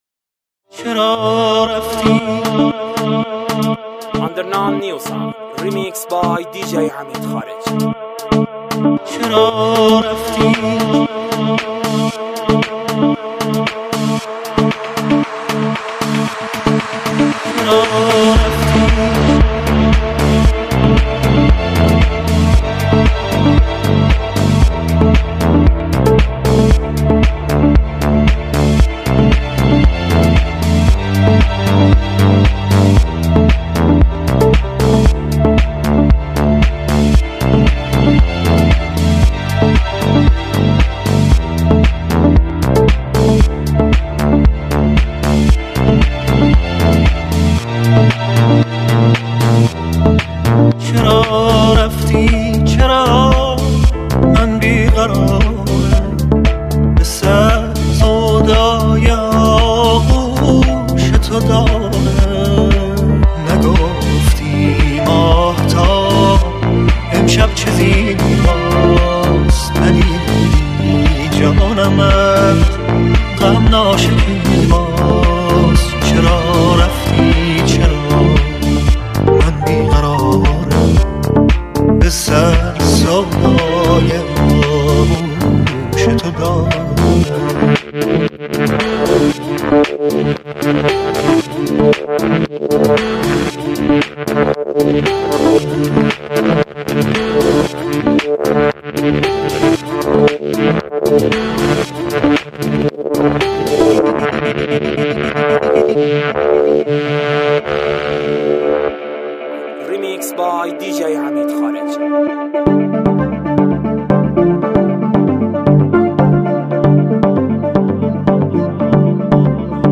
این ریمیکس برای لحظات آرام و دورهمی‌های دوستانه عالی است.